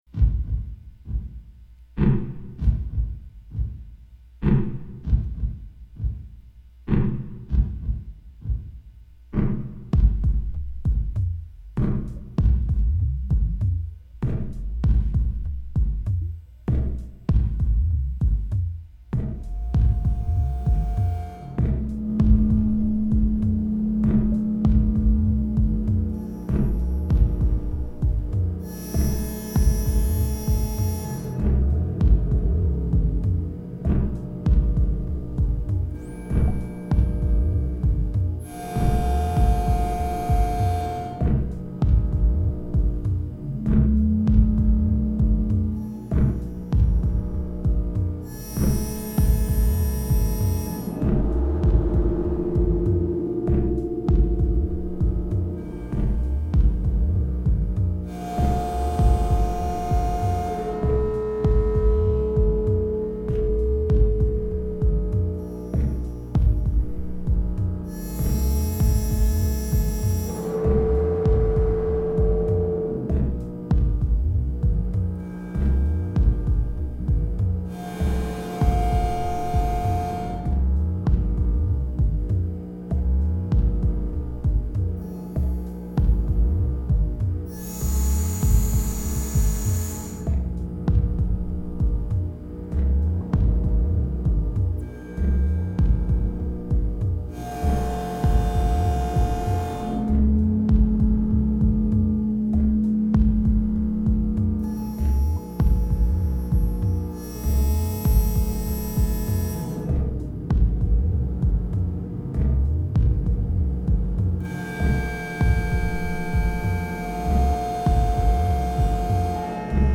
The MOTM-730 VC Pulse Divider is being driven off of the trigger out of a TR909, which along with the accompanying squishy drum treatment you hear at the beginning is the only non-MOTM thing in the mix.
Various divisor outs on the '730 are hitting a MOTM-700 Dual 2:1 Router and a pair of MOTM-820 Lags, switching between 4 detuned MOTM-300 VCOs which are cross-modulating one another and also being modulated directly by the '730.
No adjustments were made during recording - after hitting Play on the 909 this is what came out.
The mix just involved setting better relative levels between the drums/filter treatment/MOTM tracks, all of which were tidied up at the beginning and end but otherwise left exactly as recorded, barring touches of EQ, compression, or 'verb.
Industrial_Swamp_mix.mp3